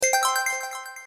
jingle-win.mp3